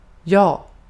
Ääntäminen
IPA: [joː]